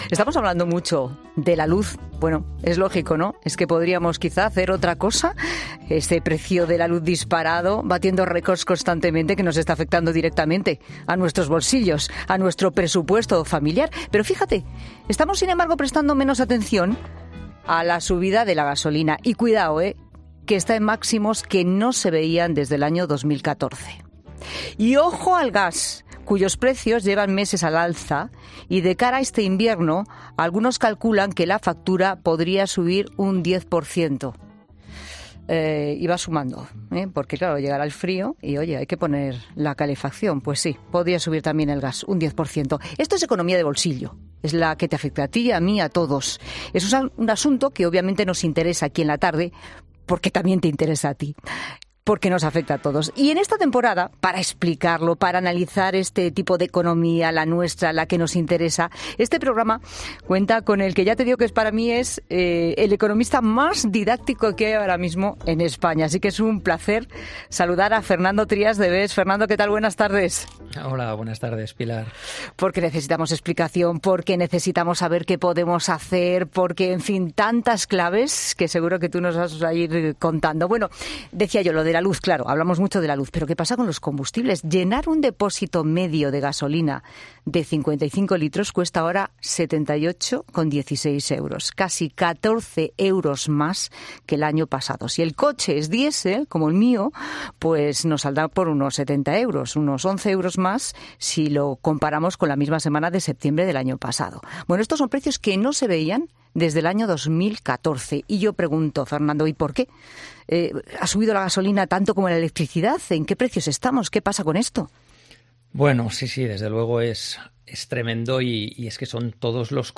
AUDIO: El economista y colaborador de 'La Tarde', Fernando Trías de Bes, explica los motivos de esta subida de los precios